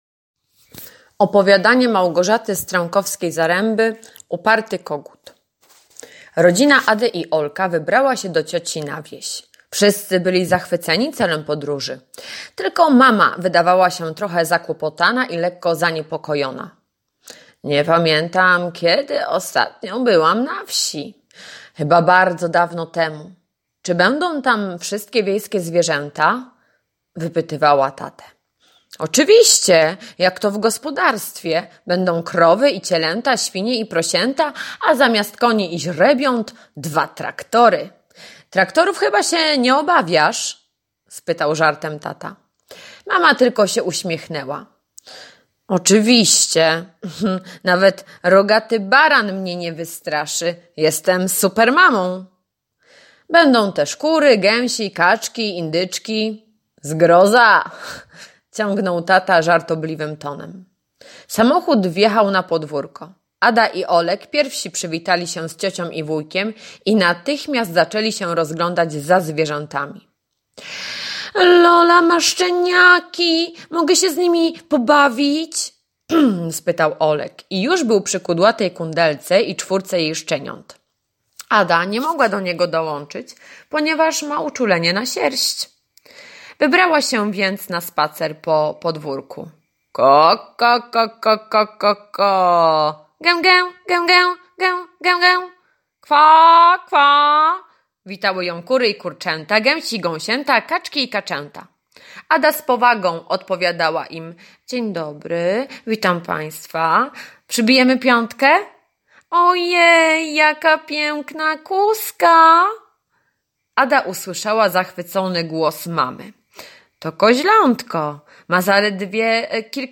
poniedziałek- słuchowisko [2.92 MB] 13.04 - długi, krótki - ćwiczenia w mierzeniu długości [310.24 kB]